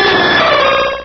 pokeemerald / sound / direct_sound_samples / cries / ho_oh.aif
-Replaced the Gen. 1 to 3 cries with BW2 rips.
ho_oh.aif